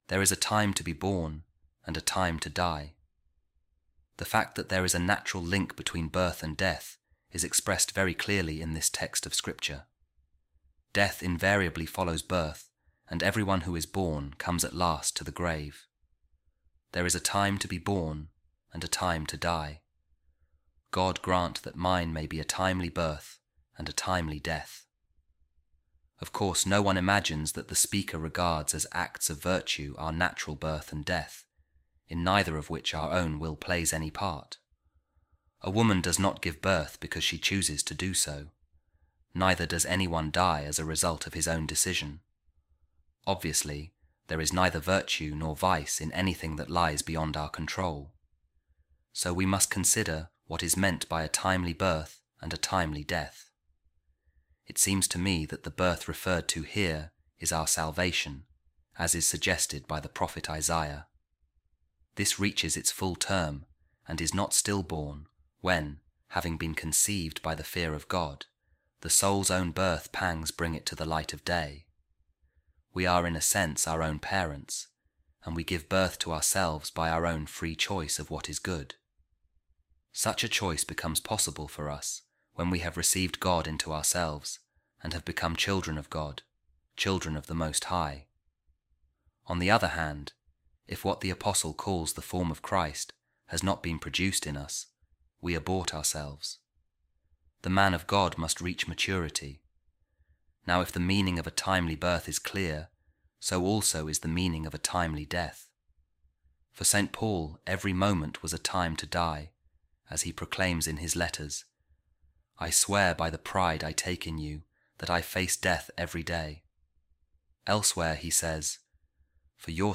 A Reading From The Homilies On Ecclesiastes Of Saint Gregory Of Nyssa | There Is A Time To Be Born, And A Time To Die